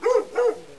dog7.wav